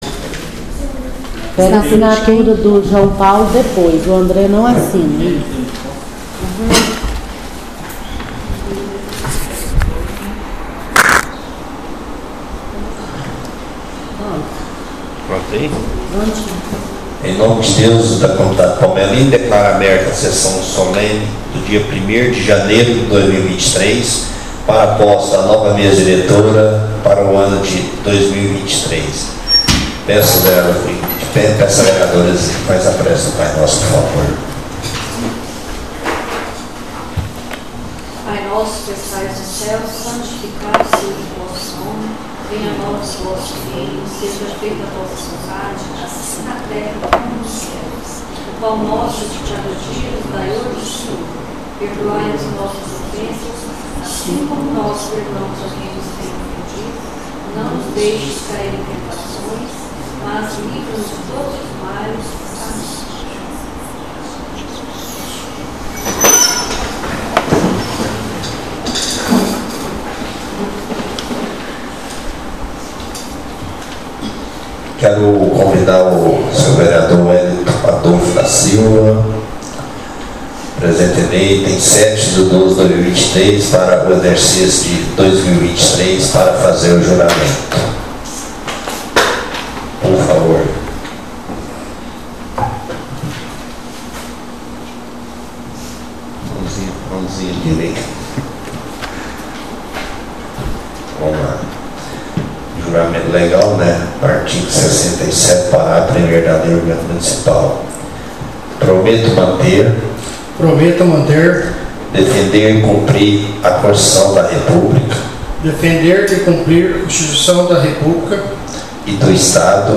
SESSÃO SOLENE DE POSSE DIA 01/01/2023